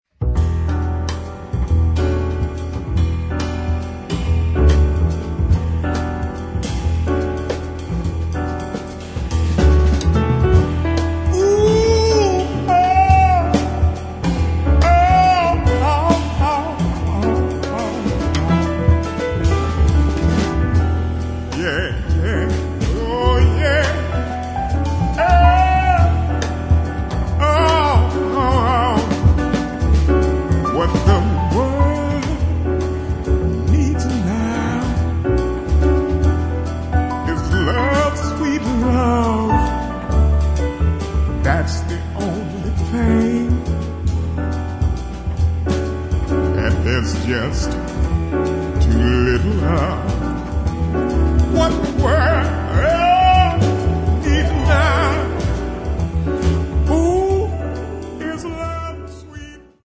[ JAZZ / SOUL ]